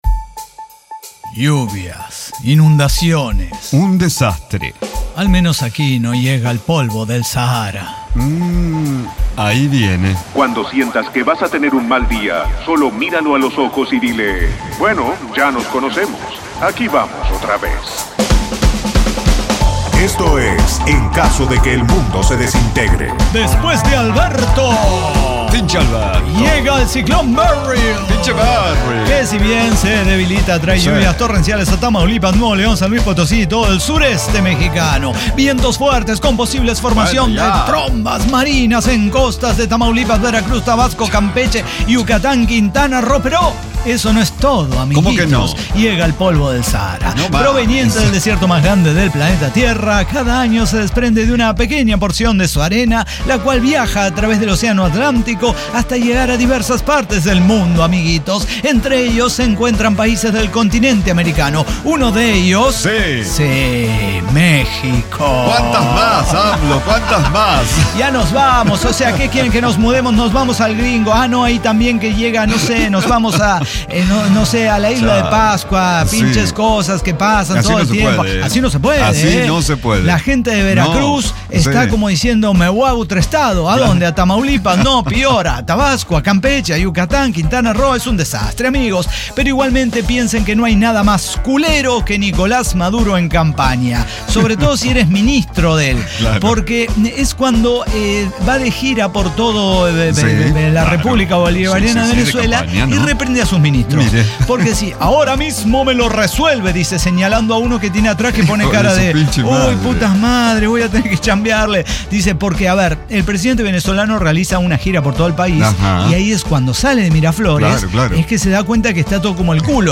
ECDQEMSD podcast El Cyber Talk Show – episodio 5812 Horrores Etílicos